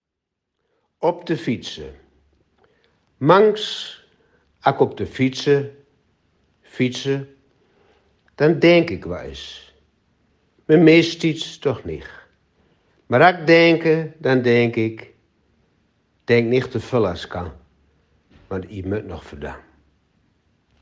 De tekst op de posters is ook ingesproken!